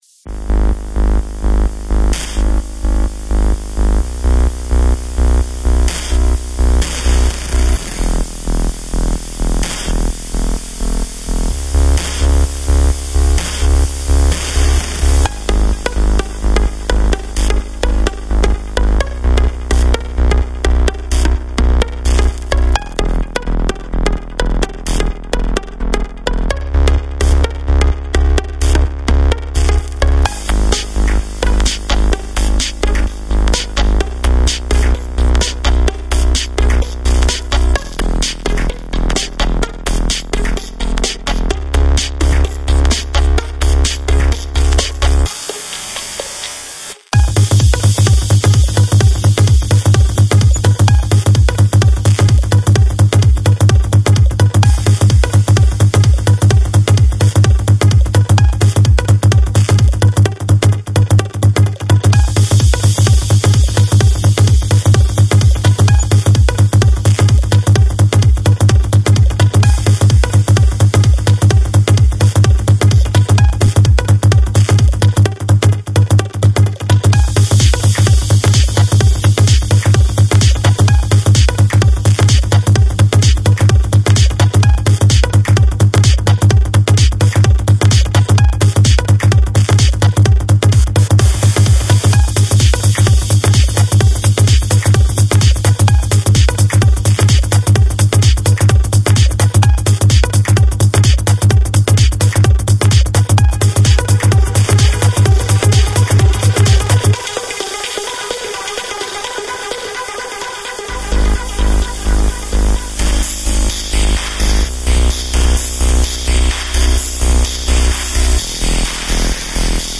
Категория: Club Music - Клубная музыка